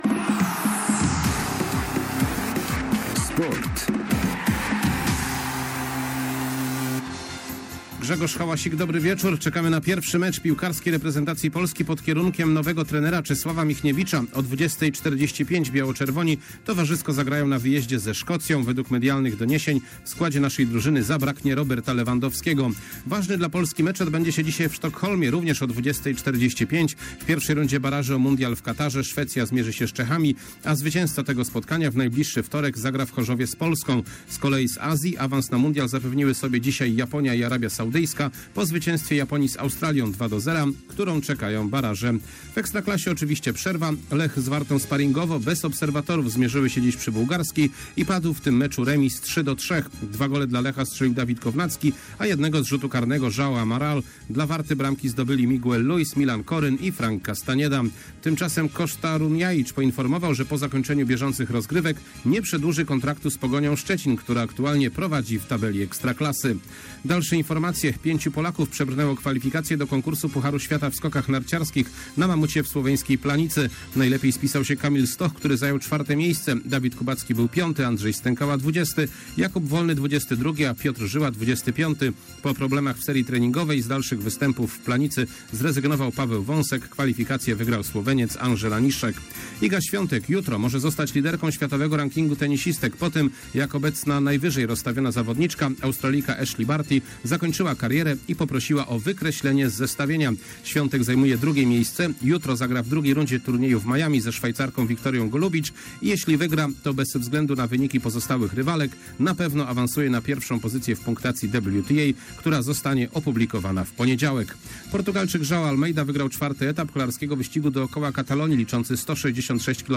24.03.2022 SERWIS SPORTOWY GODZ. 19:05